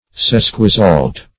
Search Result for " sesquisalt" : The Collaborative International Dictionary of English v.0.48: Sesquisalt \Ses"qui*salt\, n. [Sesqui- + salt.]